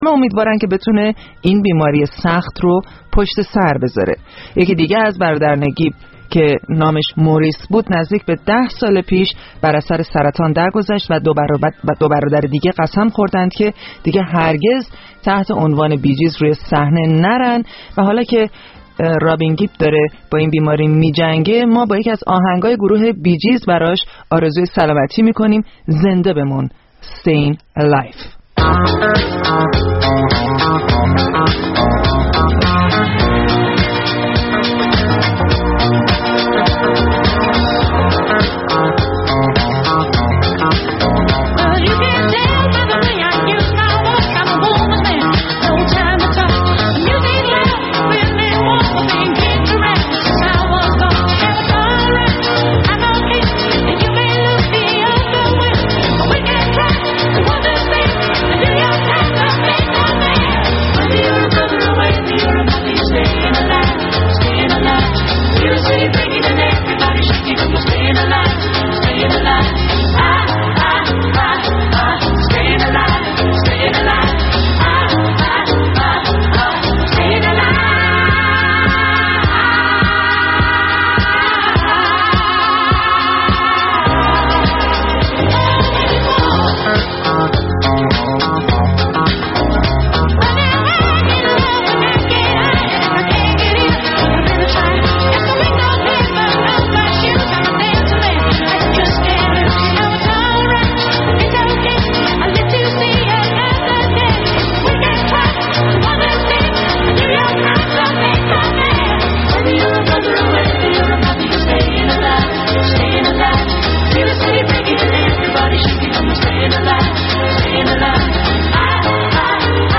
پنجشنبه‌ها از ساعت هشت شب به مدت دو ساعت با برنامه زنده موسیقی رادیو فردا همراه باشید.